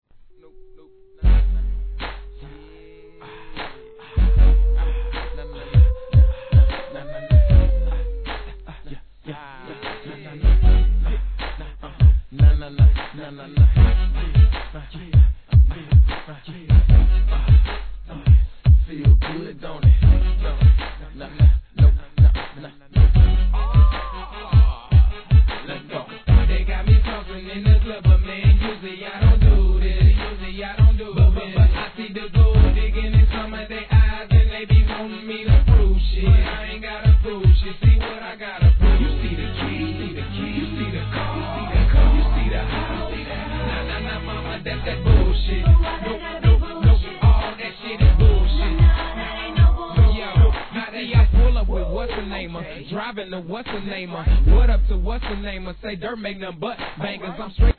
HIP HOP/R&B
サビは女性コーラスも入り、これはフロア受けもバッチリでしょ♪